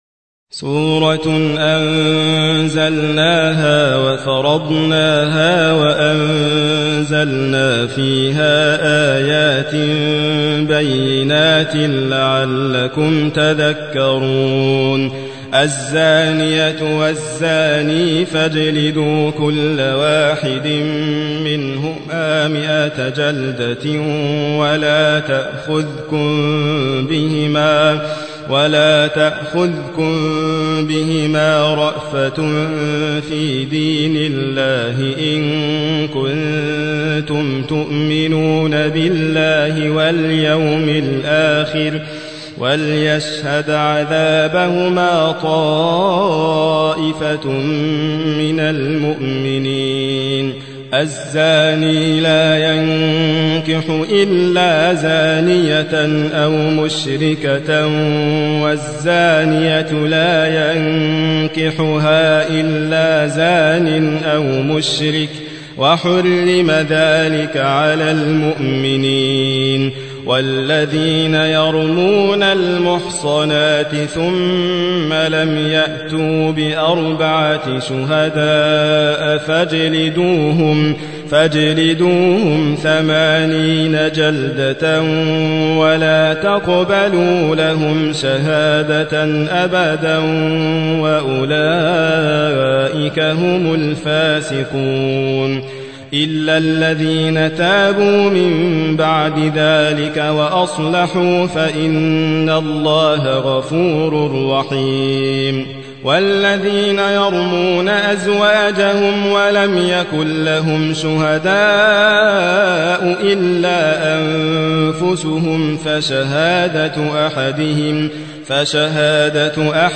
سورة النور / القارئ